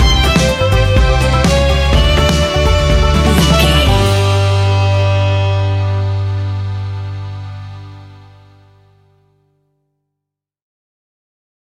Ionian/Major
Fast
acoustic guitar
mandolin
accordion